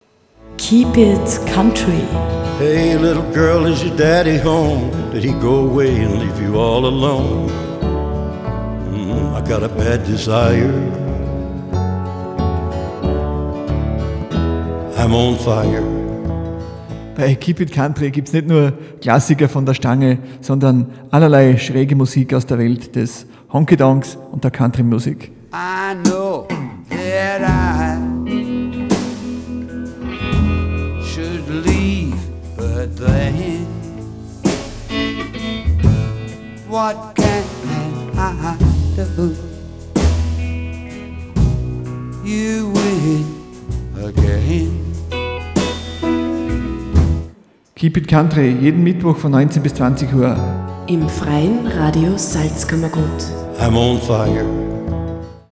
Sendungstrailer
FRS-TRAILER-KEEP-IT-COUNTRY-JEDEN-MITTWOCH.mp3